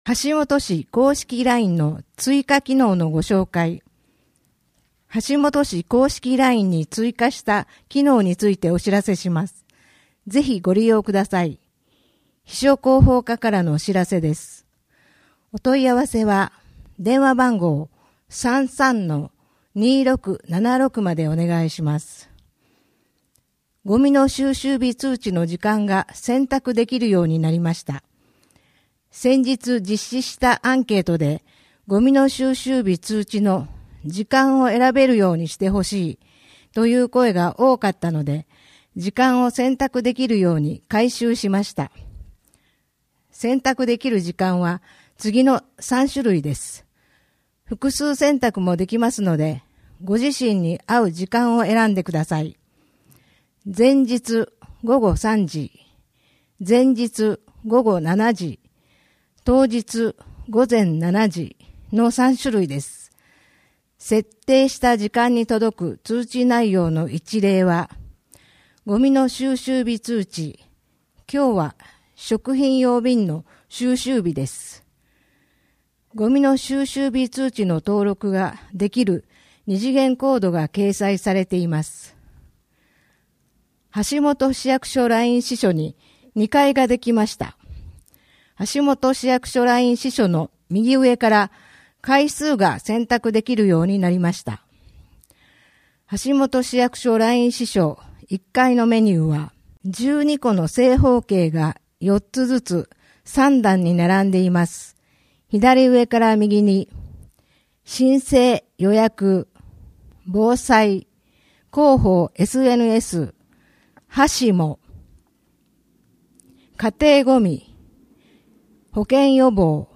WEB版　声の広報 2023年11月号